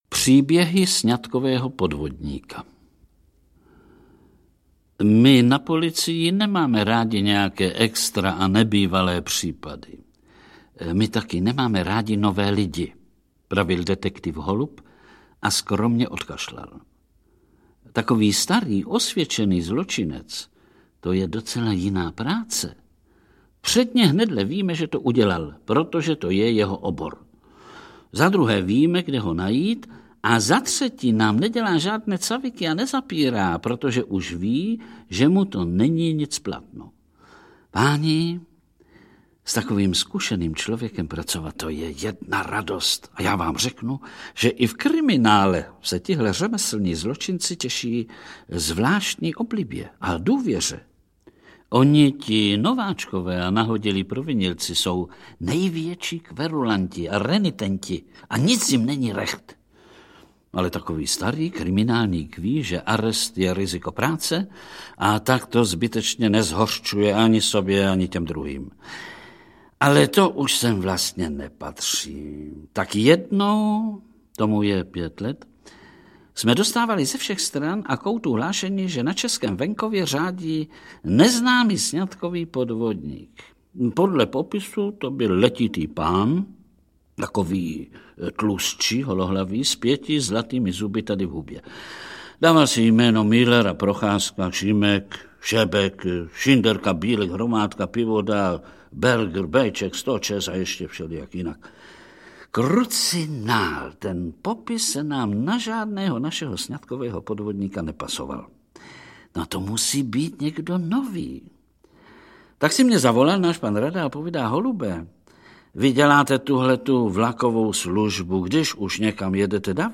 Povídky z druhé kapsy audiokniha
Ukázka z knihy
• InterpretVlastimil Brodský, Ota Sklenčka